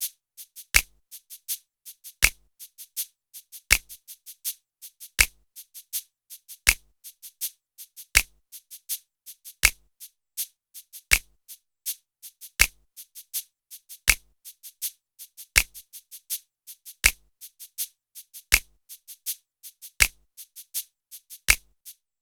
06 drums C.wav